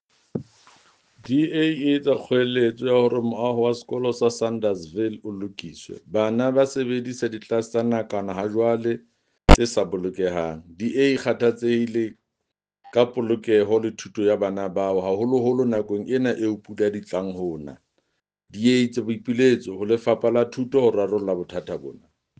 Sesotho soundbite by Jafta Mokoena MPL with pictures here, here, and here